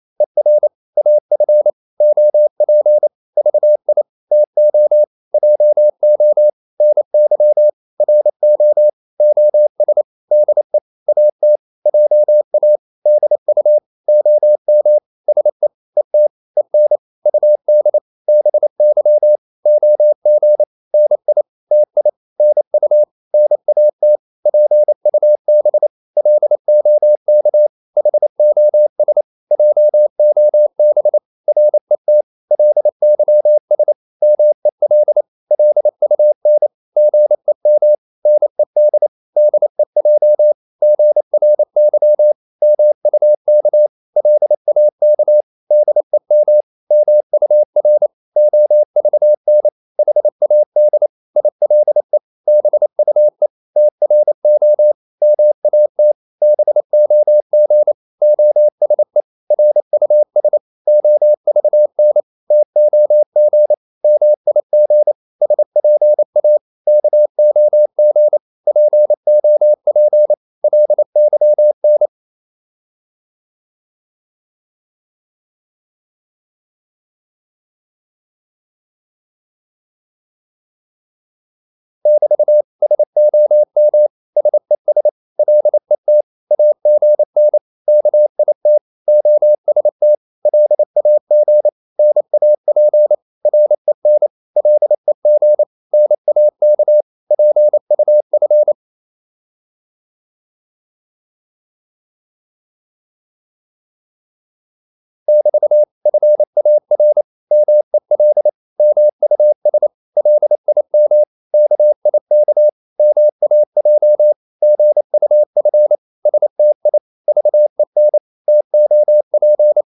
Korte danske ord 28wpm | CW med Gnister
Korte ord DK 28wpm.mp3